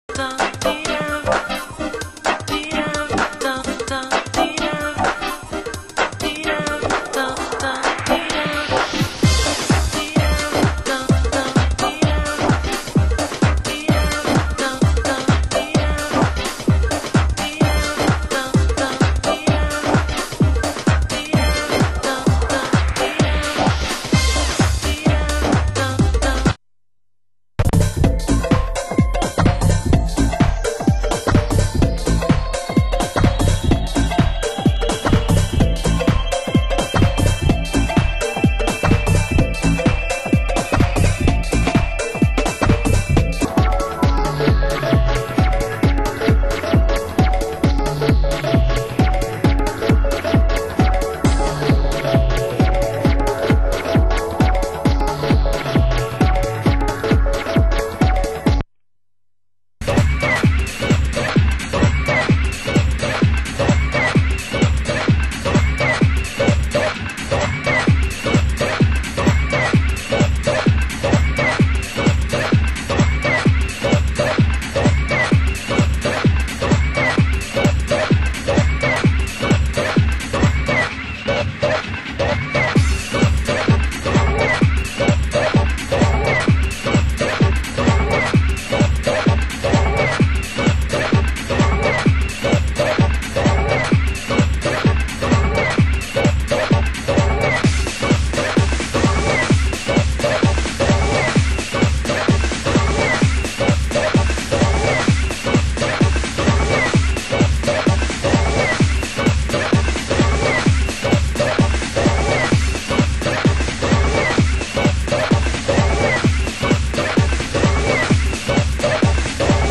Genre Funky House